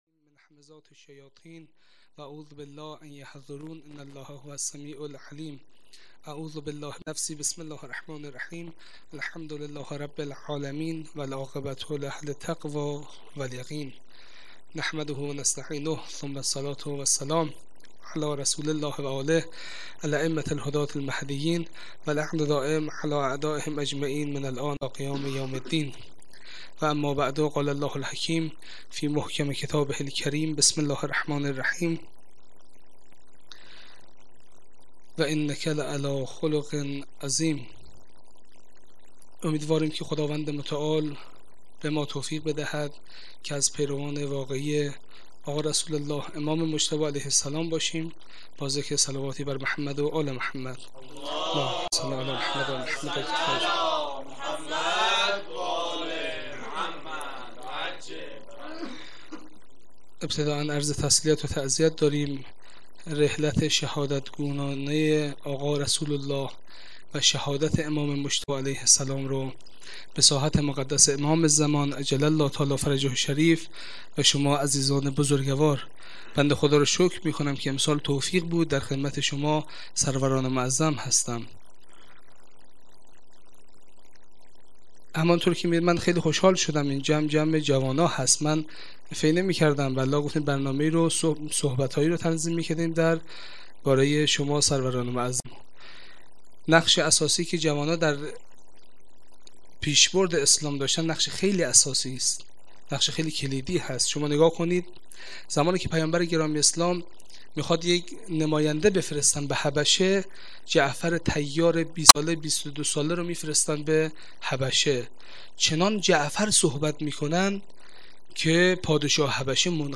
1-sokhanrani.mp3